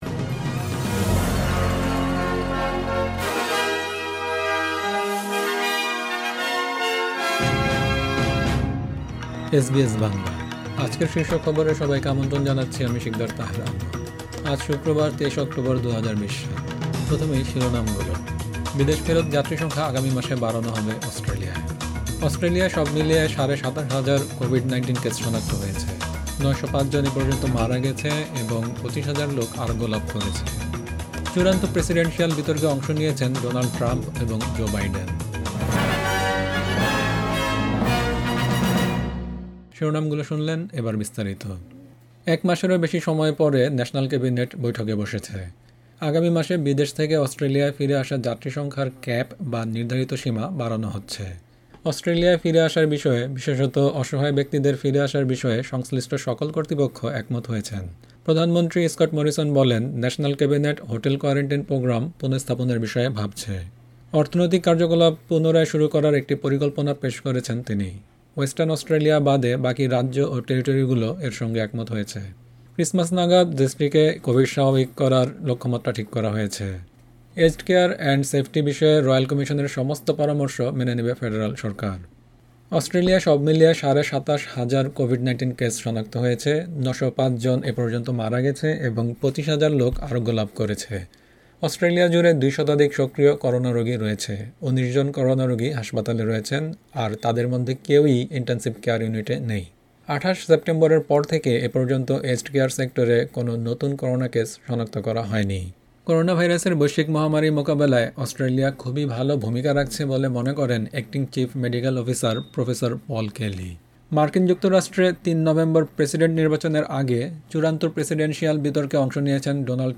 এসবিএস বাংলা শীর্ষ খবর: ২৩ অক্টোবর ২০২০
SBS News Bangla Program: 23 October 2020 Source: SBS